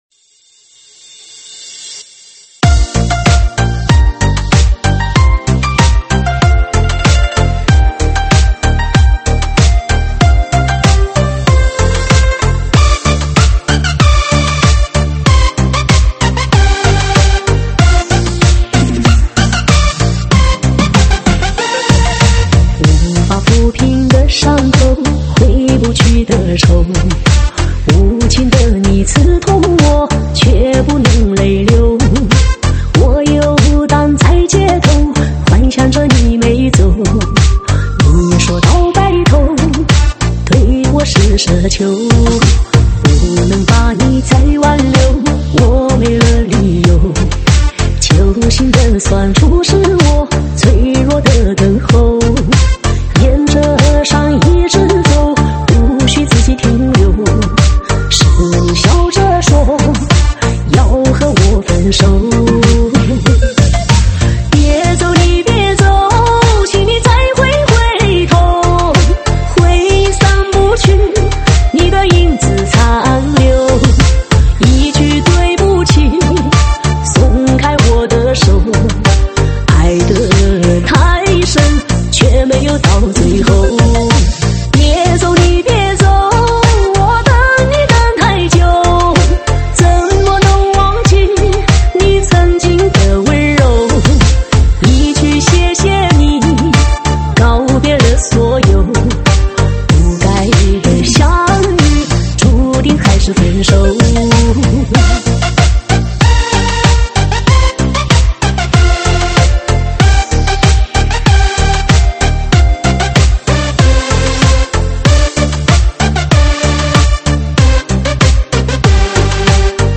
舞曲类别：中文Club